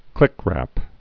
(klĭkrăp)